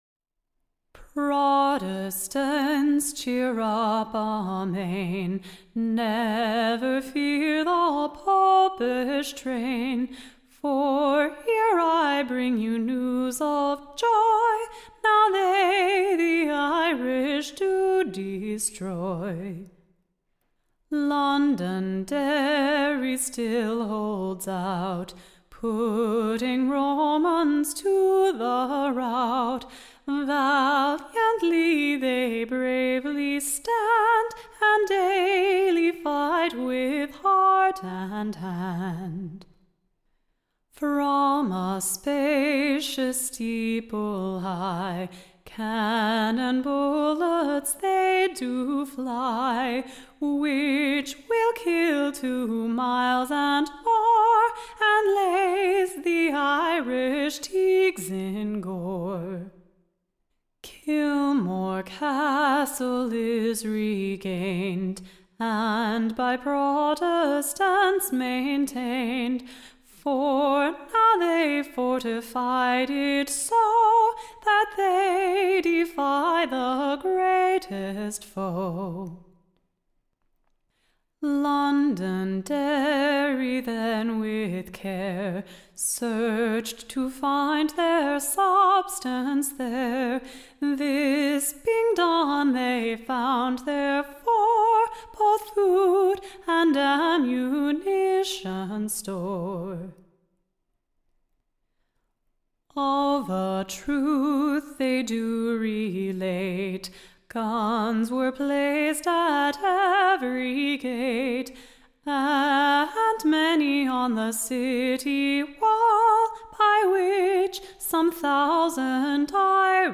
Recording Information Ballad Title The Glory of London-Derry: / OR, / The Couragious Protestants Success on the French and Irish Army. Tune Imprint To the Tune of, The Leggan Water Or, Nanny Oh.